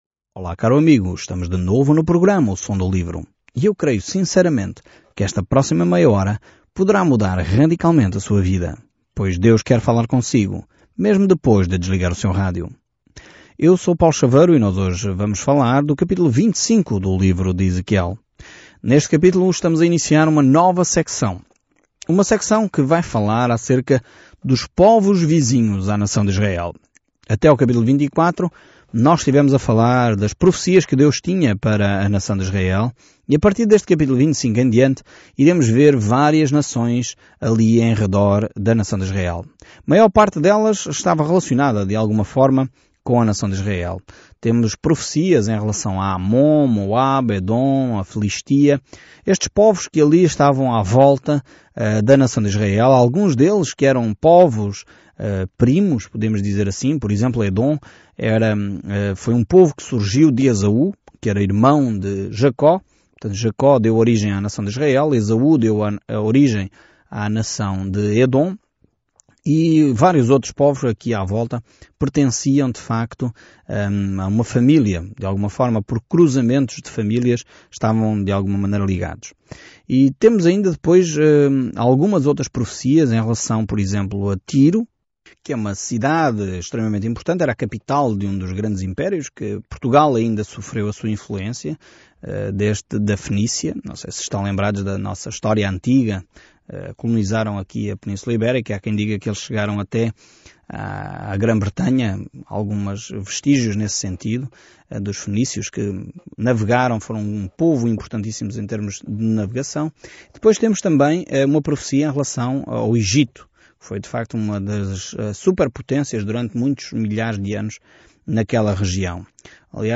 Scripture Ezekiel 25 Ezekiel 26 Day 14 Start this Plan Day 16 About this Plan O povo não quis ouvir as palavras de advertência de Ezequiel para retornar a Deus, então, em vez disso, ele encenou as parábolas apocalípticas, e isso perfurou o coração das pessoas. Viaje diariamente por Ezequiel enquanto ouve o estudo em áudio e lê versículos selecionados da palavra de Deus.